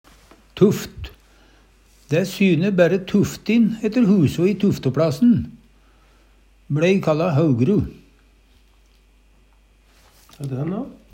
tuft - Numedalsmål (en-US)